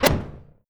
EXPLOSION_Arcade_03_mono.wav